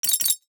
NOTIFICATION_Metal_14_mono.wav